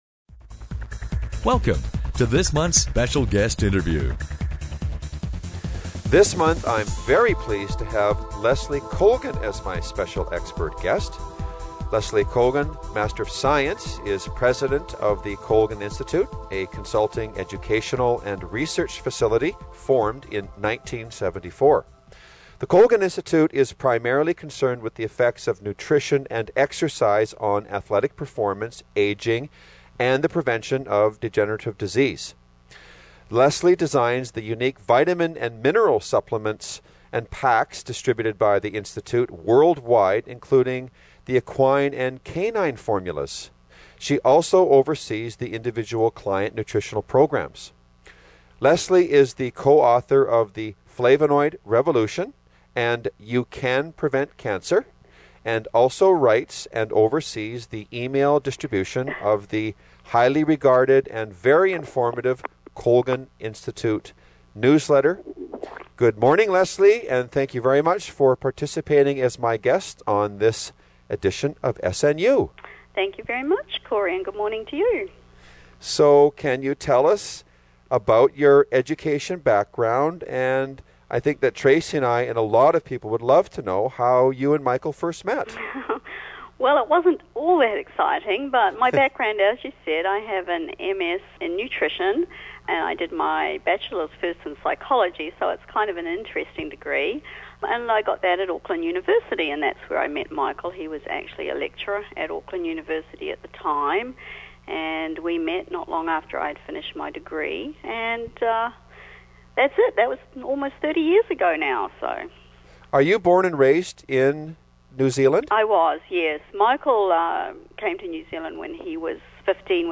Special Guest Interview Volume 6 Number 2 V6N2c